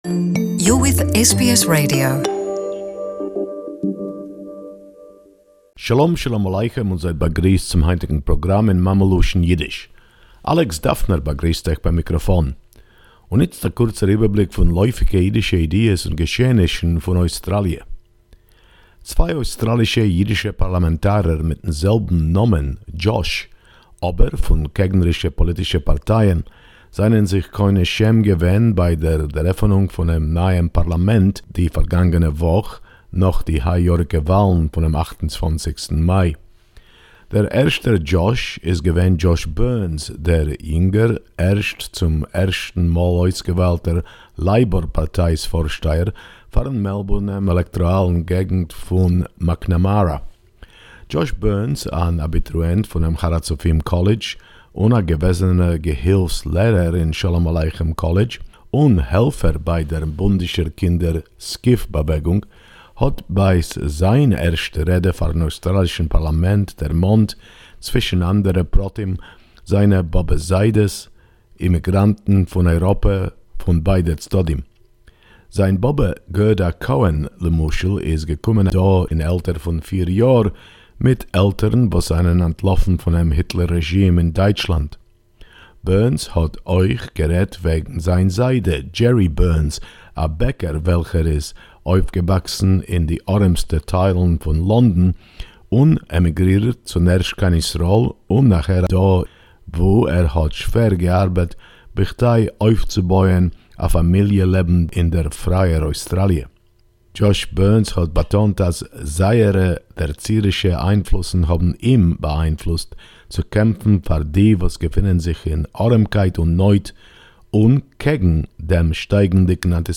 News for the Yiddish speaking community, as for 28.7.19